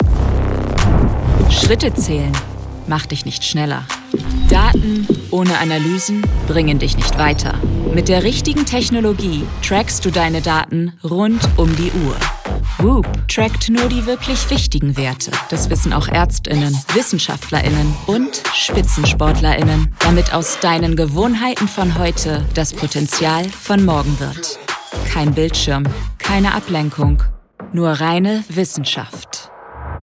Microphone: Rode NT2-A Interface: Volt 1 Sound booth: takustic
Sprechprobe: Werbung (Muttersprache):
I work from a fully equipped home studio, ensuring high-quality audio and quick turnaround times. My voice is versatile and adaptable, and I am reliable and flexible.